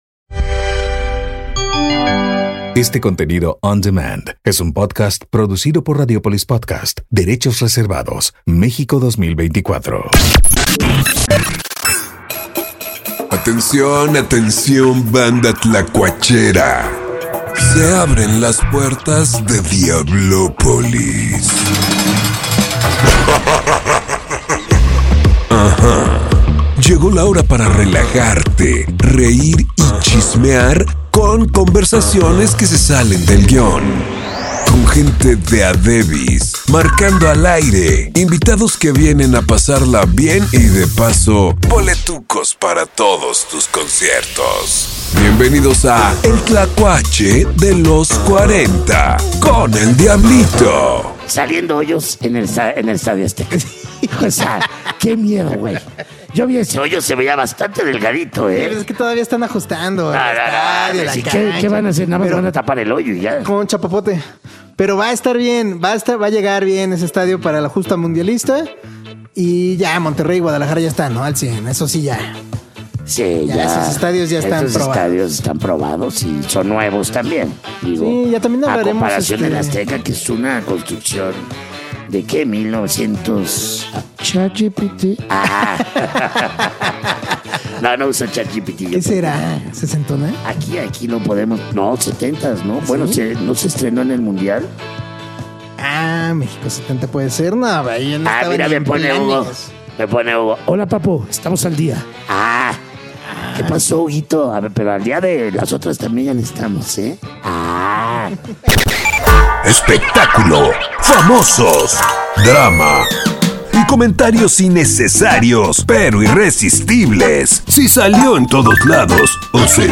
Misticismo en Diablopolis 91:53 LOS40 Mexico Liquits en vivo desde Diablopolis… Cuéntanos tu experiencia como conductor en “Historias De Un Taxi”…